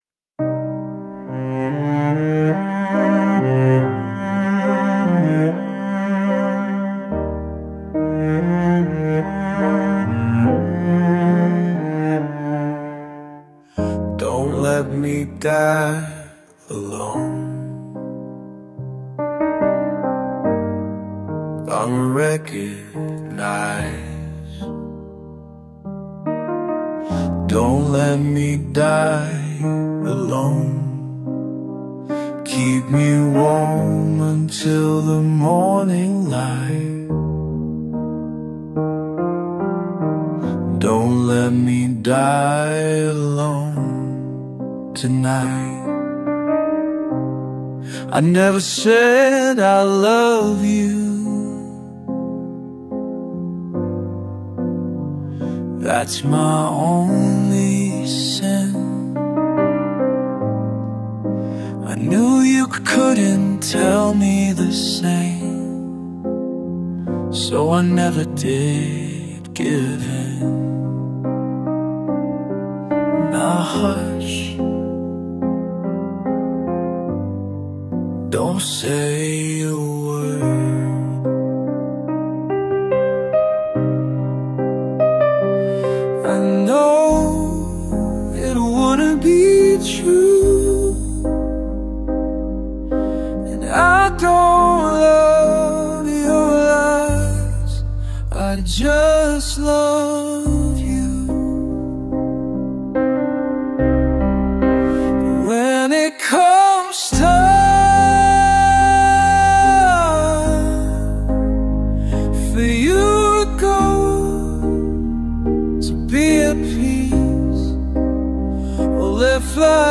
[lo-fi]